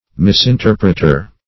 Misinterpreter \Mis`in*ter"pret*er\, n. One who interprets erroneously.
misinterpreter.mp3